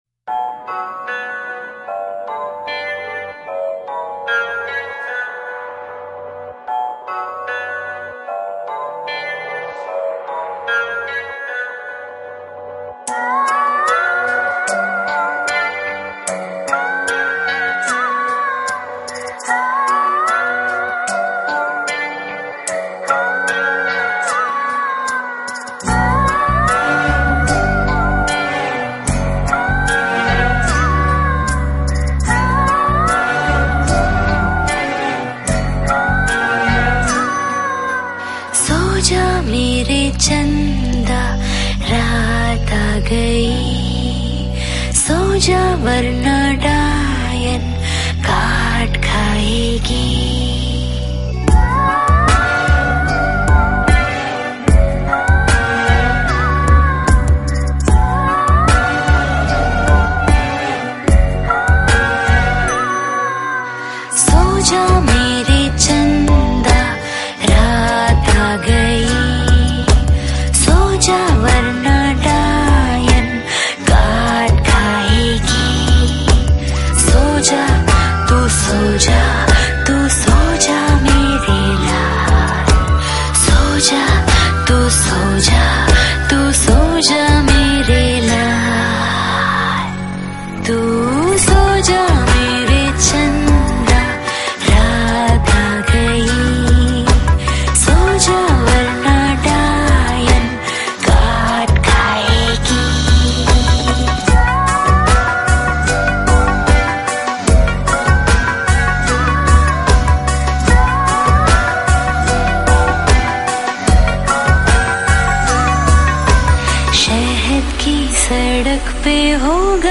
Low Quality